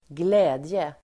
Uttal: [²gl'ä:dje]